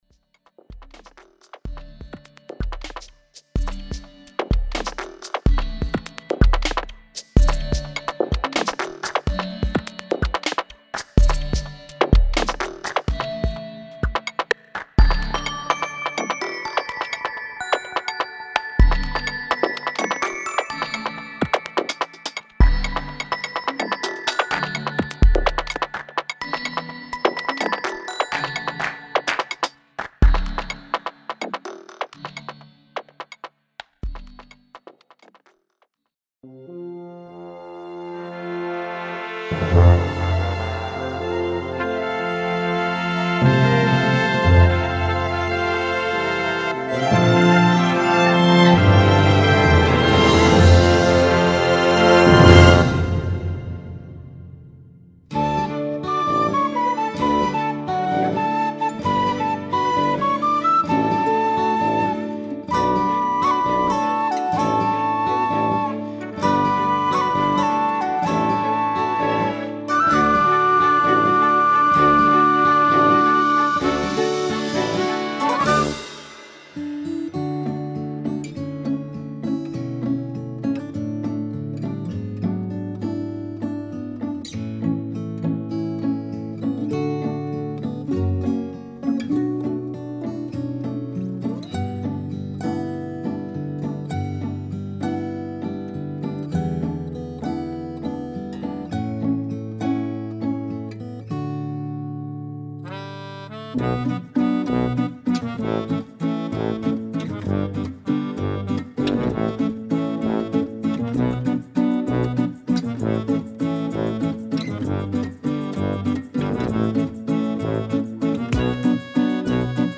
また、映像本編が始まる前のイントロパートは、メインテーマとなるメロディーを使用し、エレクトロアレンジを施しました。
INSTRUMENTAL ACOUSTIC / CLASSIC